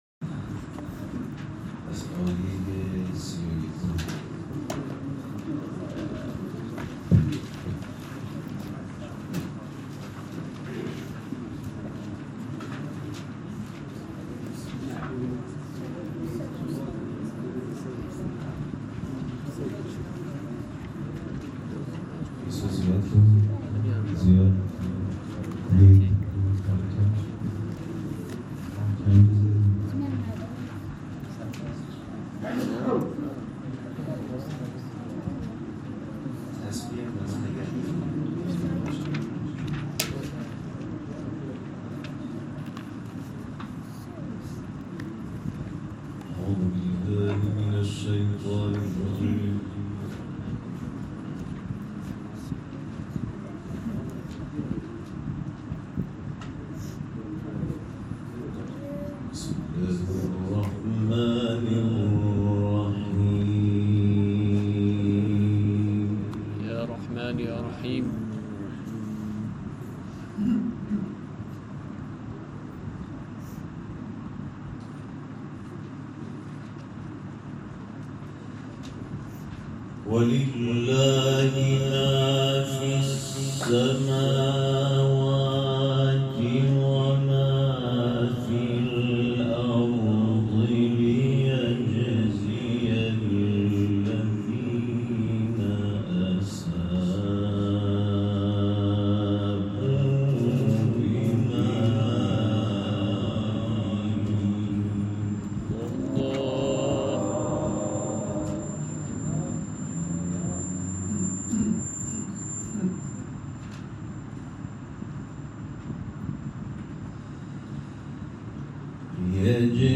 اعضای کاروان قرآنی اعزامی به مناطق مرزی سیستان و بلوچستان شب گذشته در مجمع قاریان این استان حاضر شده و به تلاوت آیاتی از کلام‌الله مجید پرداختند.